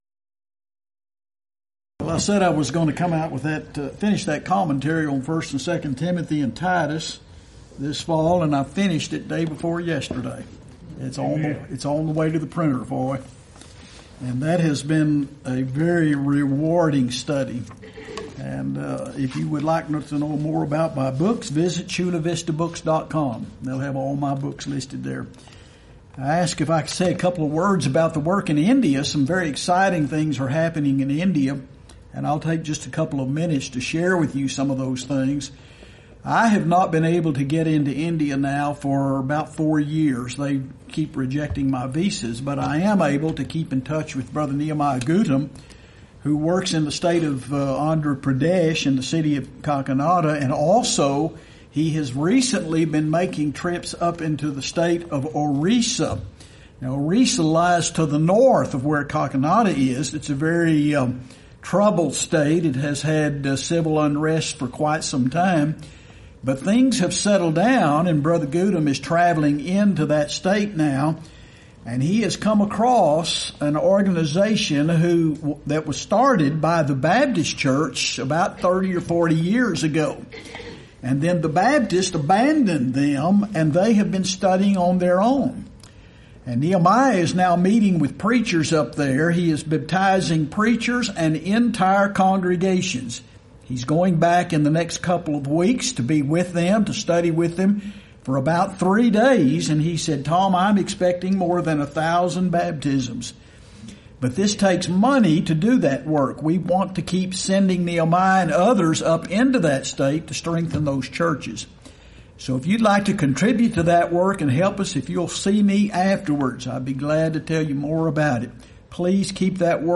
Event: 26th Annual Lubbock Lectures
lecture